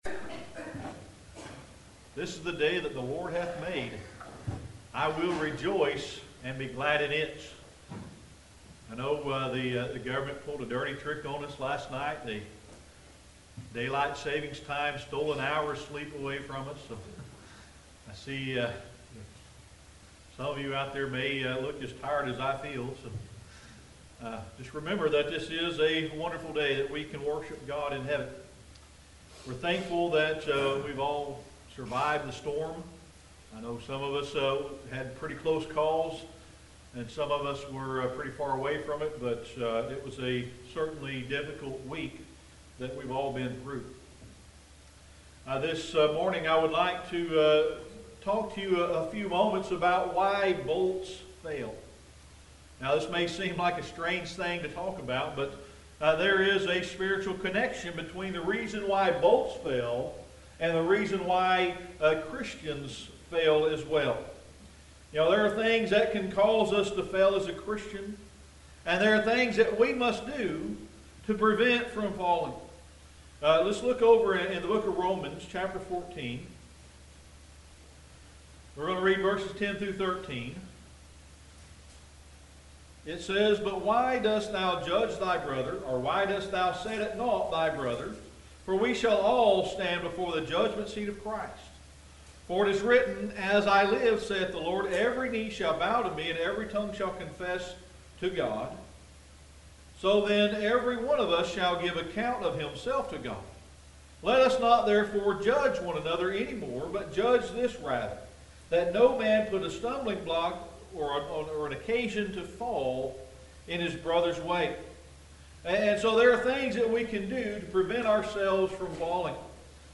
Audio Sermon: Why Bolts Fail